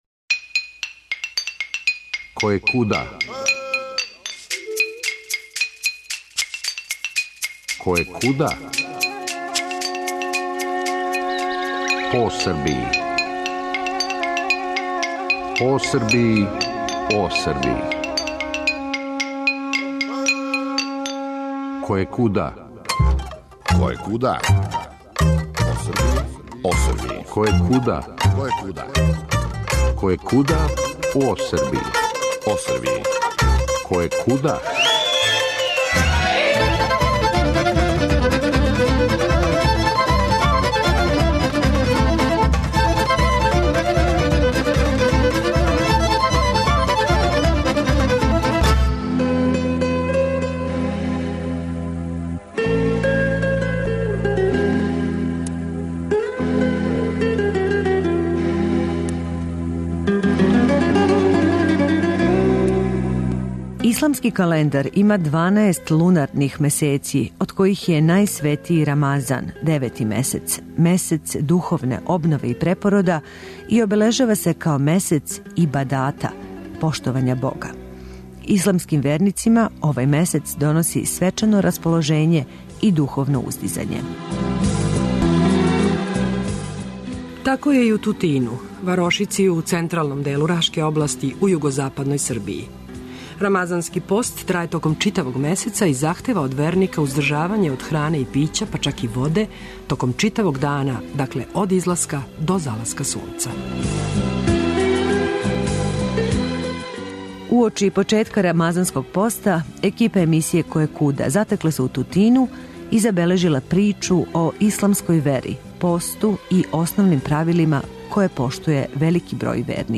Уочи почетка рамазанског поста екипа емисије Којекуда затекла се у Тутину и забележила причу о исламској вери, посту и основним правилима које поштује велики број верника.